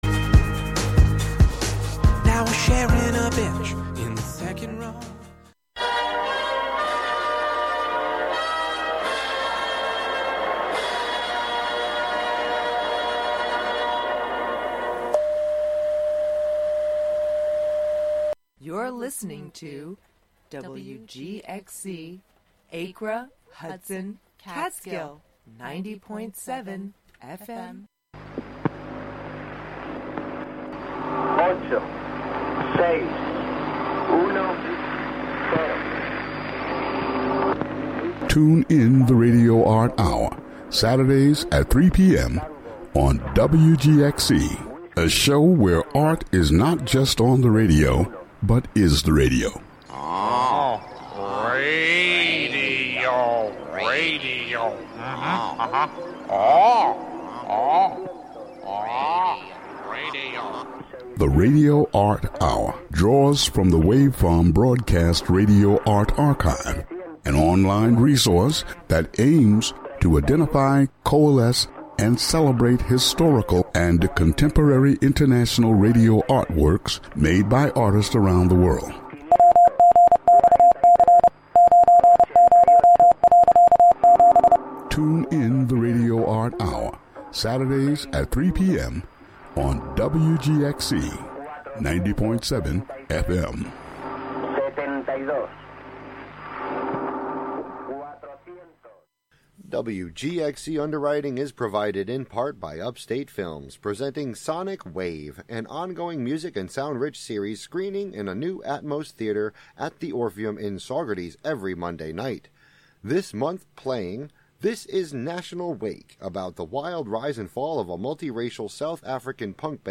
sound art, radio and transmission art, experimental music